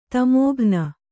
♪ tamōghna